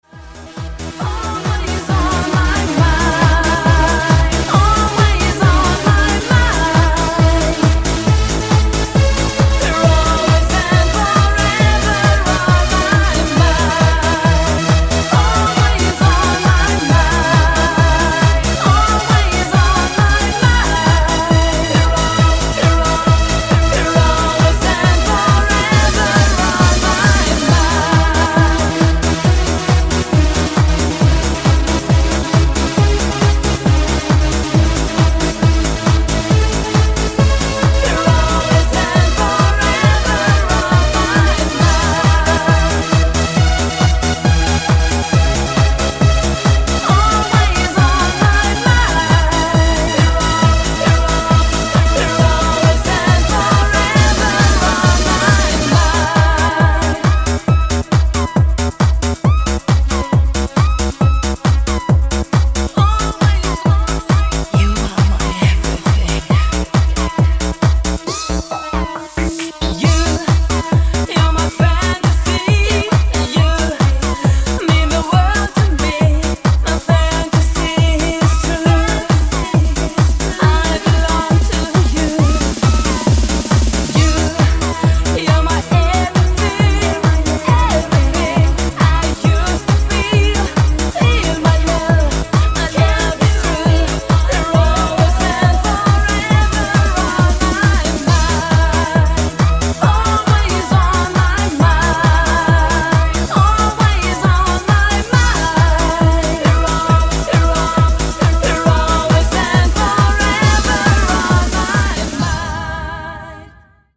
- Muzyka elektroniczna